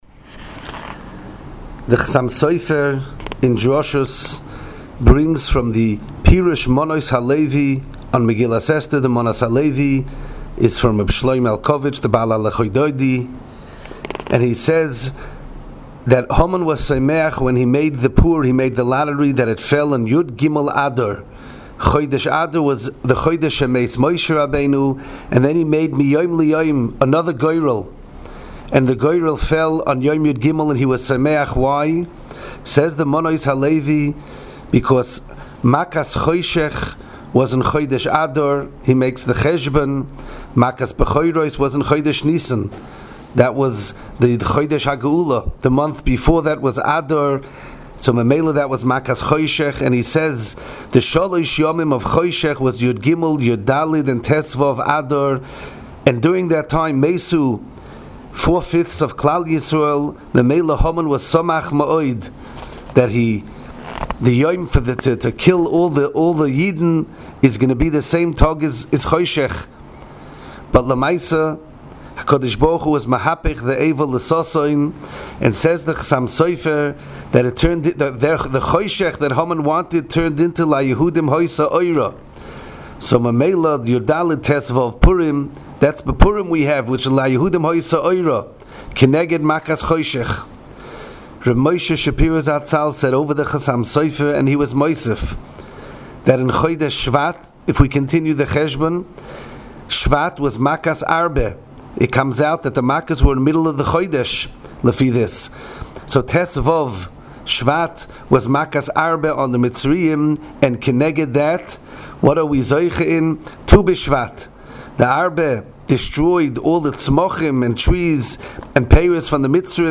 Inspiring Divrei Torah, Shiurim and halacha on Parshas Bo from the past and present Rebbeim of Yeshivas Mir Yerushalayim.